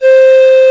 WoodenFlute_C5_22k.wav